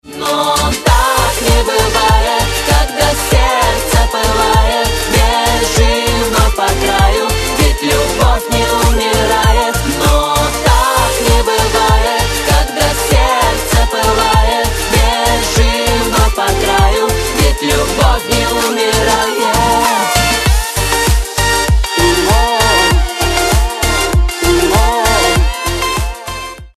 танцевальные